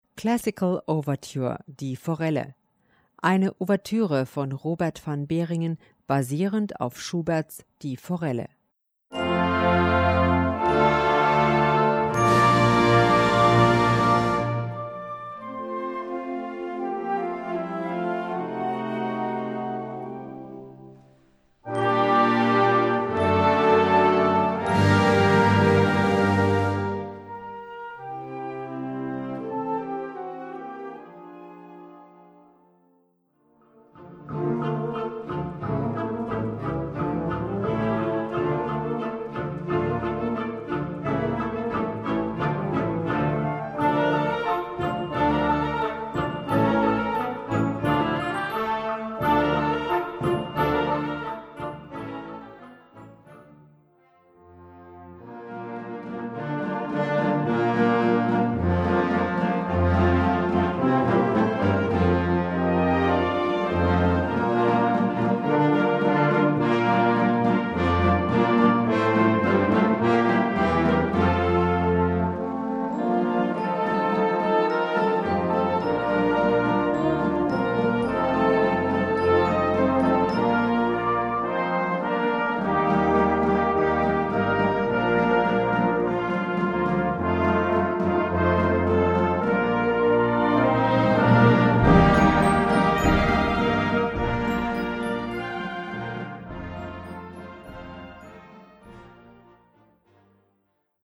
Gattung: Ouvertüre
Besetzung: Blasorchester